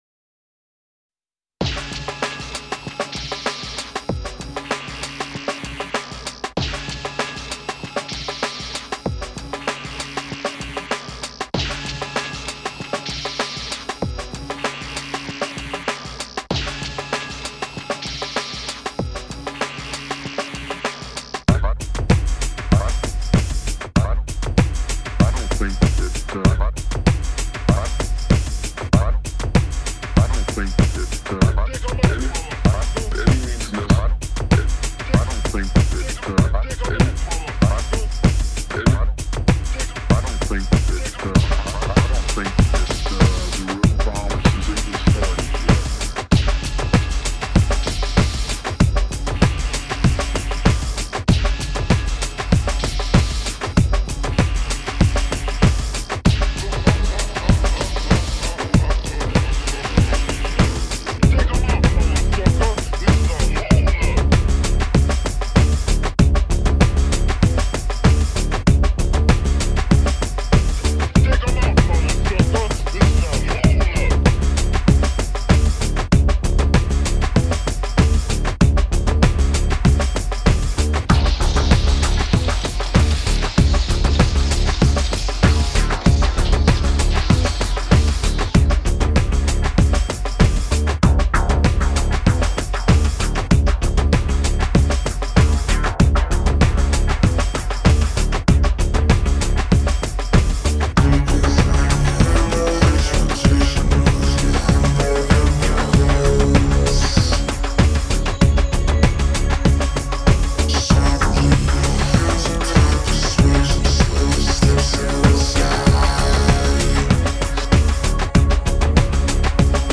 Electro Bloody Music